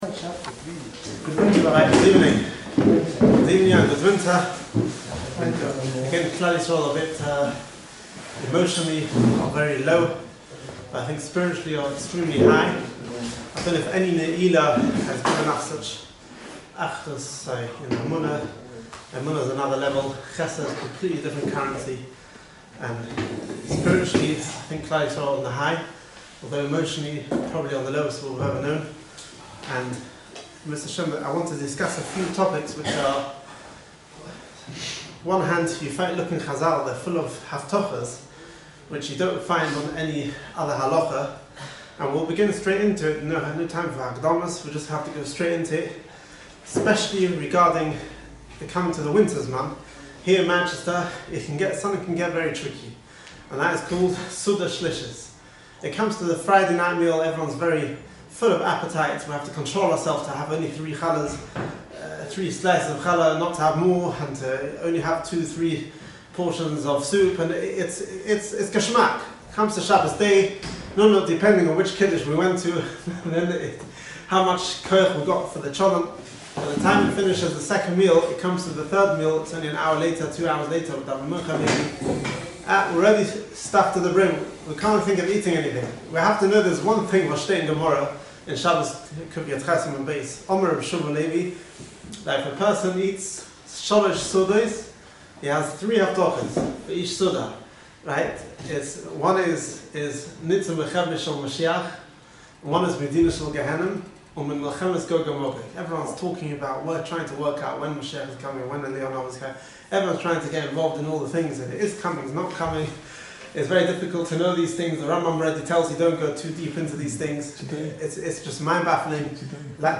shiur